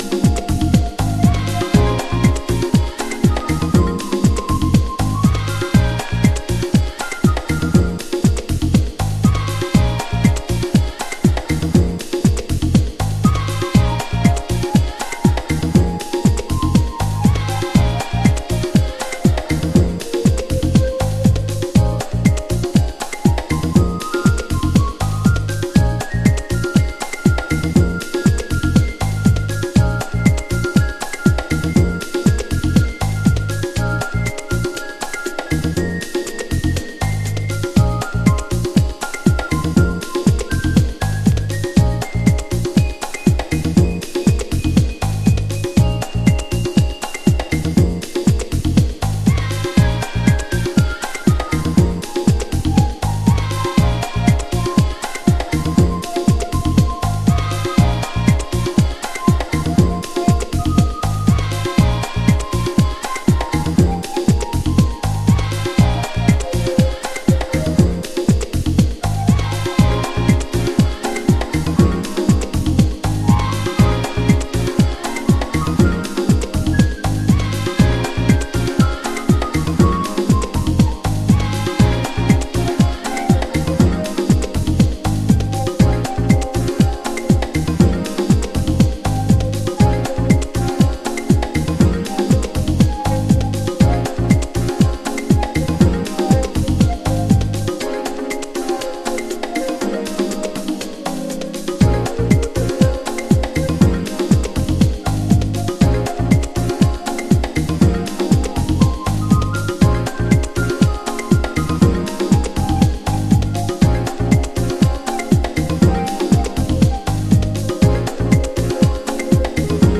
軽やかなアフリカン・パーカションにキッズ・チャントとくれば、コレは鉄板でしょう。
Chicago Oldschool / CDH